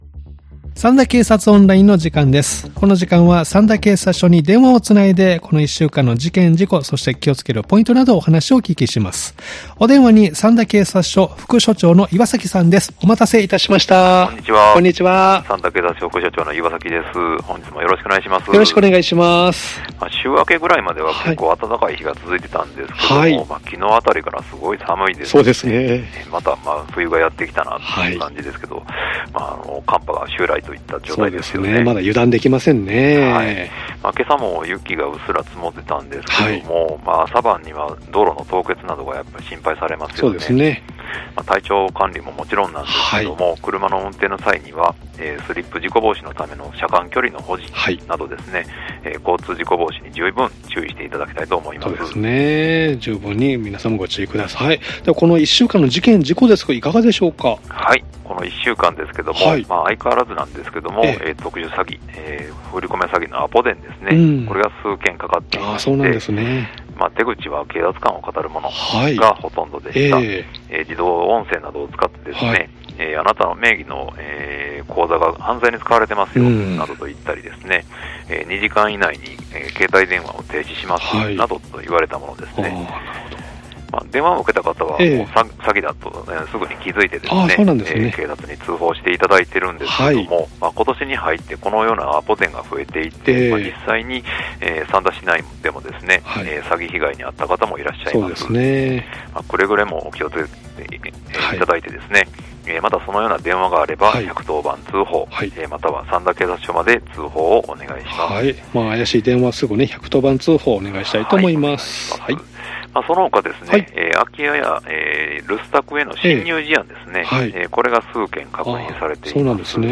三田警察署に電話を繋ぎ、一週間の事件事故、防犯情報、警察からのお知らせなどをお聞きしています（再生ボタン▶を押すと番組が始まります）